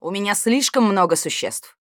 Те же самые фразы, но уже в исполнении актрисы озвучивания.